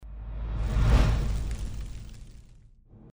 Звук огня для монтажа перехода
Короткий звук с треском пламени